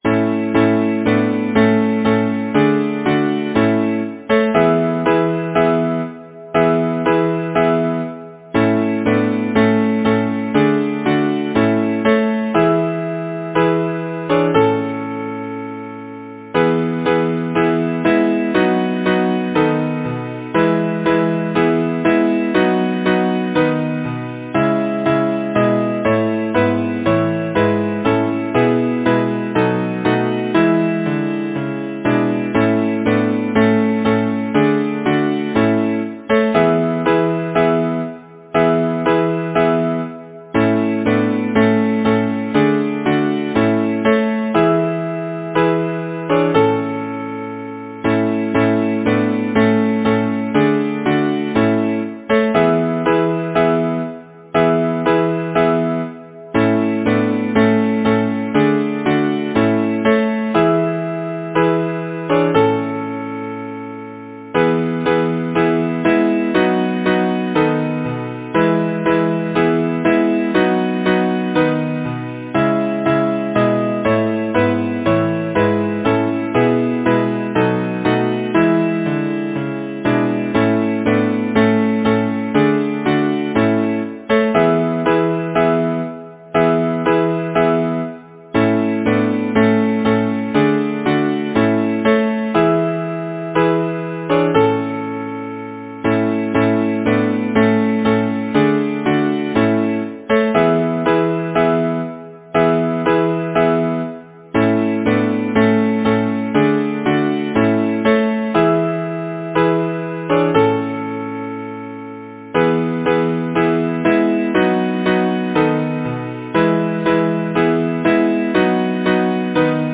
Title: May song Composer: William James Robjohn Lyricist: Number of voices: 4vv Voicing: SATB Genre: Secular, Partsong
Language: English Instruments: A cappella